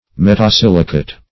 Metasilicate \Met`a*sil"i*cate\, n.